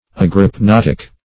Search Result for " agrypnotic" : Wordnet 3.0 ADJECTIVE (1) 1. of or pertaining to agrypnia ; The Collaborative International Dictionary of English v.0.48: Agrypnotic \Ag`ryp*not"ic\, n. [Gr.
agrypnotic.mp3